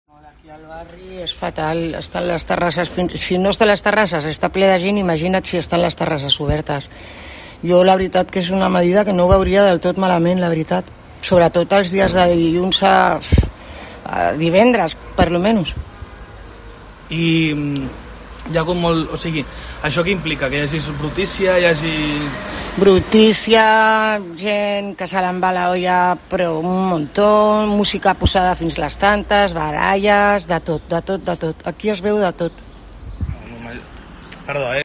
DECLARACIONES DE VECINOS DE CIUTAT VELLA